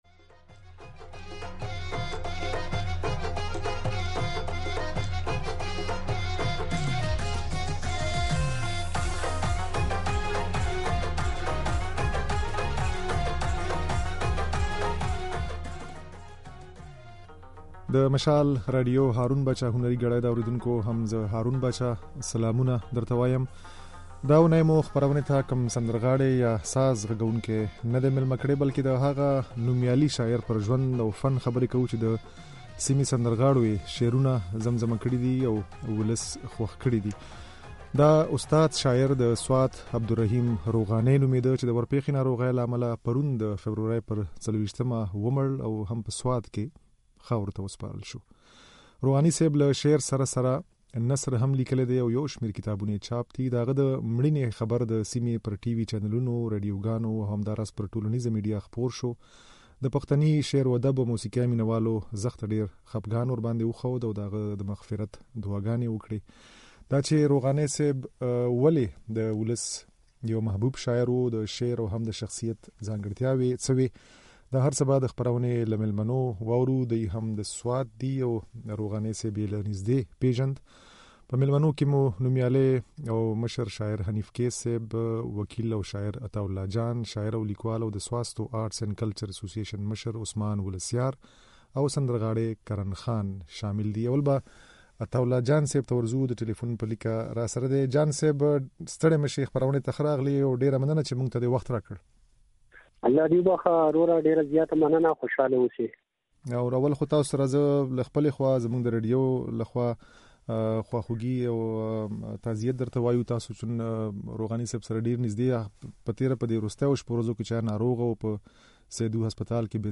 د عبدالرحيم روغاني پر مړينه د سيمې د شاعرانو او هنرمندانو تاثرات
په خپرونه کې د دوی د خبرو ترڅنګ له موسيقۍ سره د عبدالرحيم روغاني ځينې غزلونه هم اورېدای شئ.